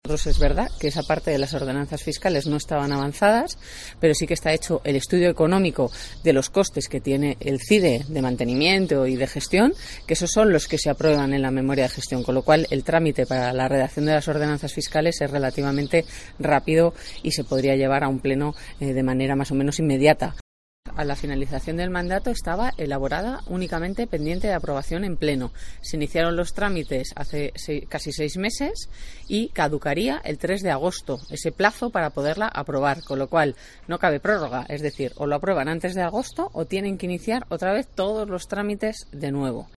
Clara Martín, portavoz del PSOE en el Ayuntamiento de Segovia